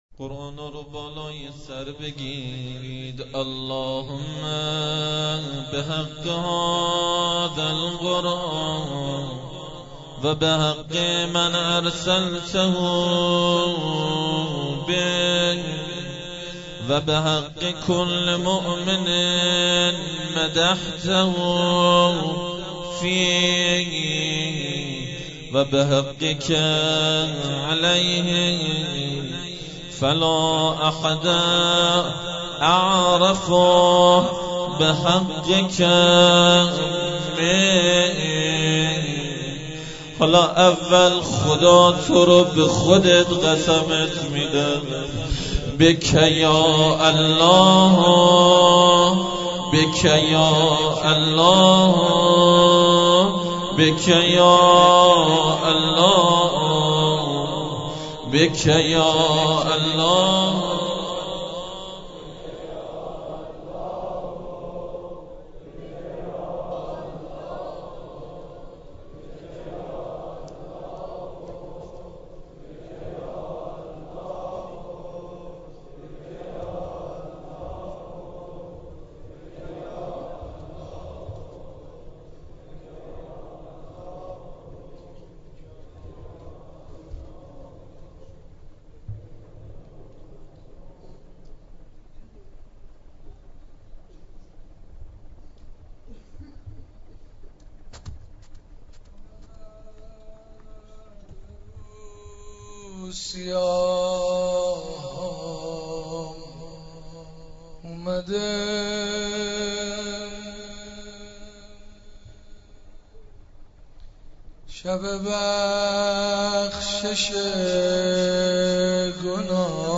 حسینیه حضرت زینب (سلام الله علیها)